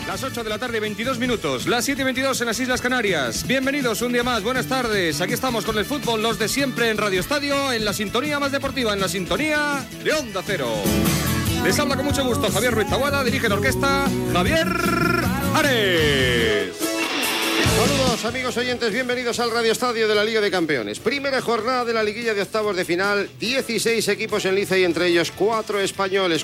Hora, inici del programa
Esportiu